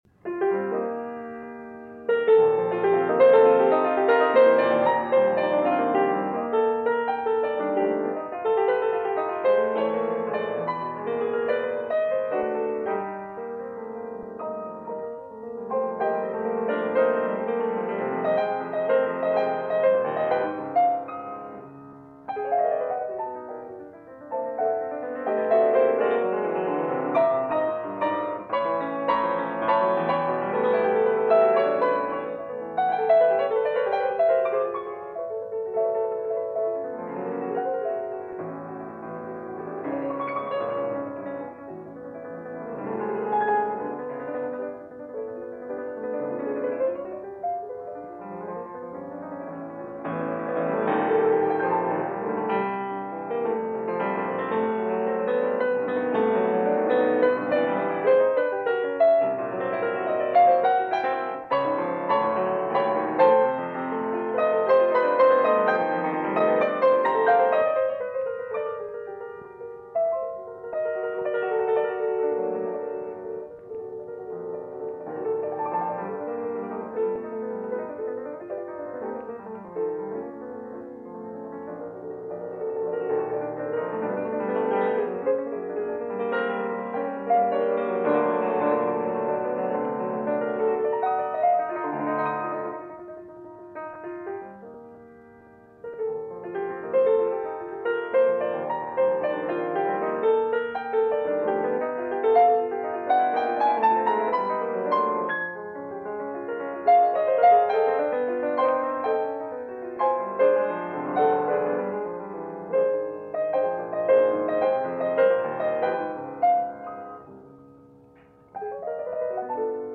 Sonatine Number 3 – Yvonne Lefébure, piano
The concerts were recorded by ORTF in Paris and were broadcast via their transcription service, from which this performance is part of a series of performances by her and other soloists.
Here is that broadcast recording of Maurice Emmanuel’s Sonatine Number 3, as performed by Yvonne Lefébure during the 1962 Centenary festival concert.
Maurice-Emmanuel-Sonatine-No.-3-1962-concert-.mp3